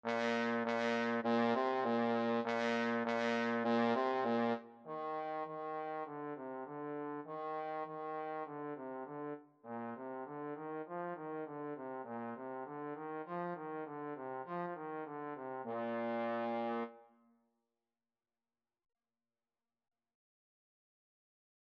Bb3-F4
2/4 (View more 2/4 Music)
Trombone  (View more Beginners Trombone Music)
Classical (View more Classical Trombone Music)